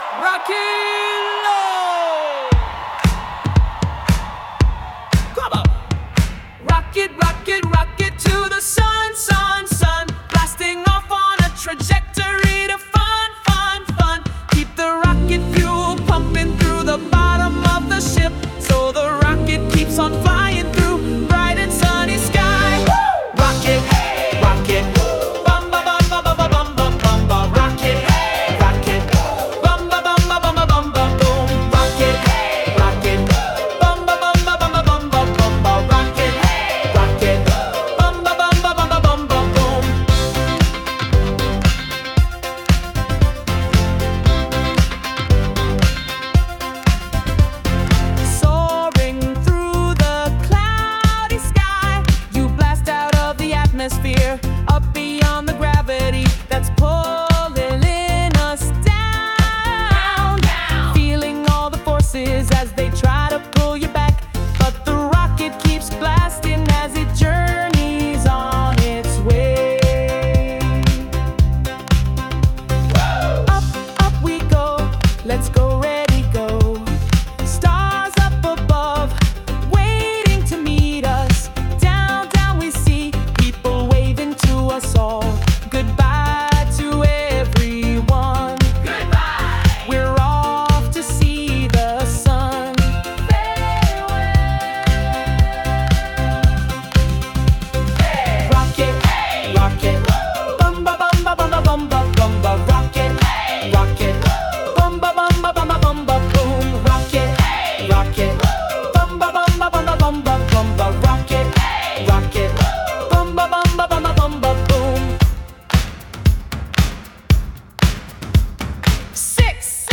Sound Imported : Tumbly Lumpy Grumble
Sung by Suno